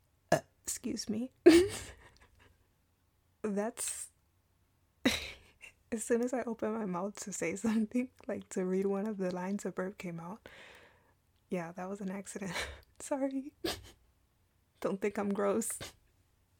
"[Blooper] or Burper?"
burp blooper.mp3